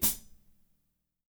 -16  HAT25-L.wav